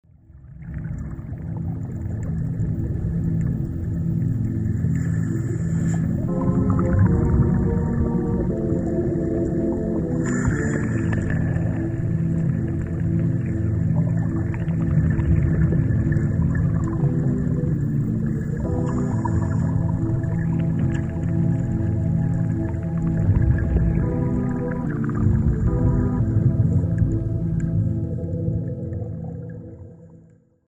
Style: Ambient
Tief unter der Wasseroberfläche blubbert und brodelt es.